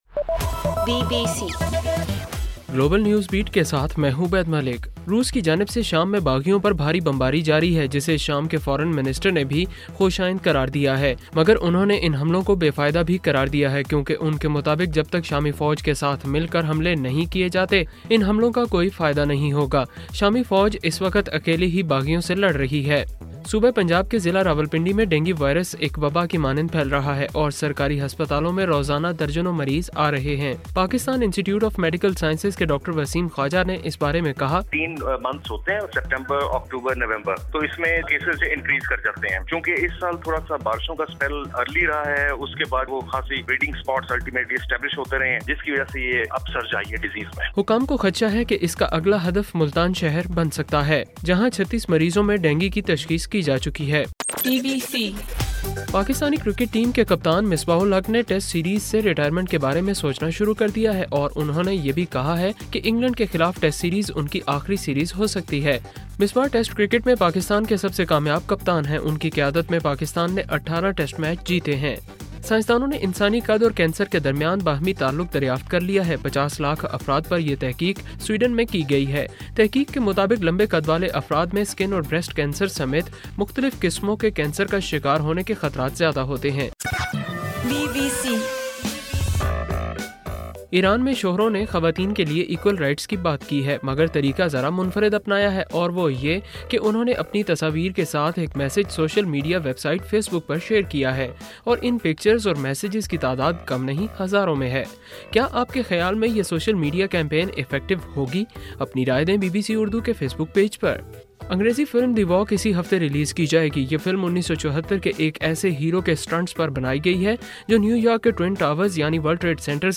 اکتوبر 2: رات 12 بجے کا گلوبل نیوز بیٹ بُلیٹن